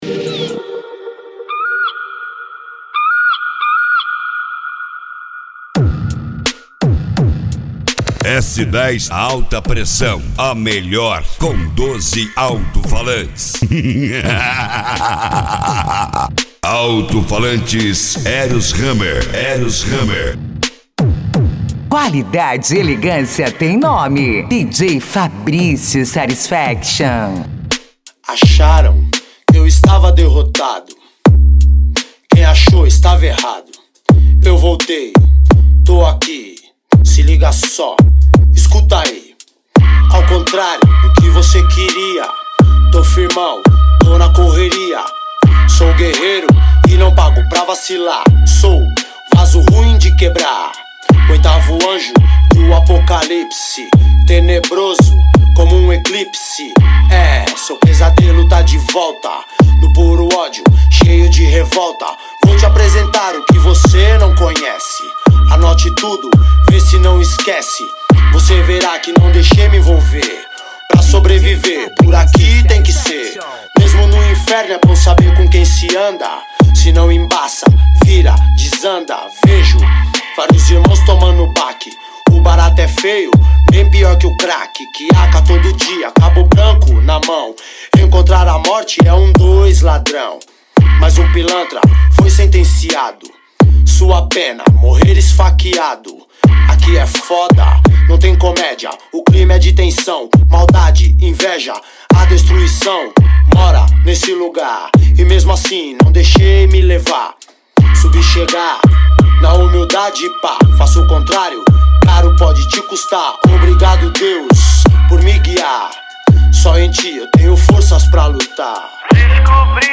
Bass
Funk